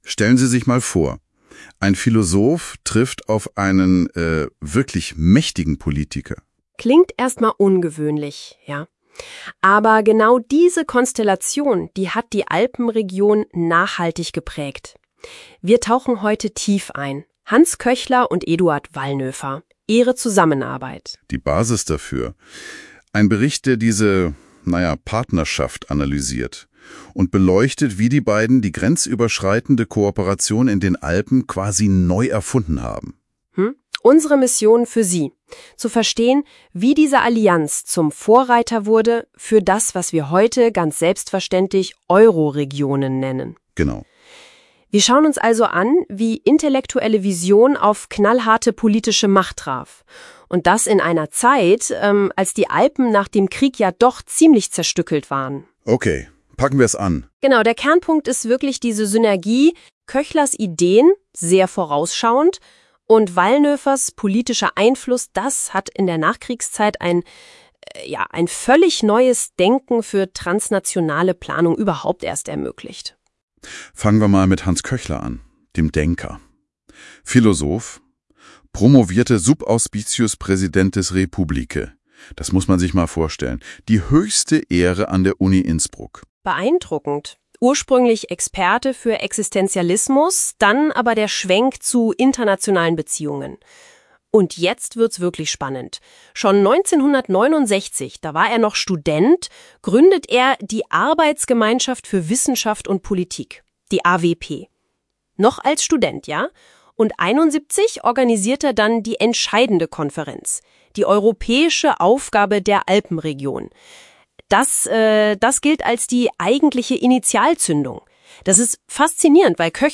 Gemini-generated audio review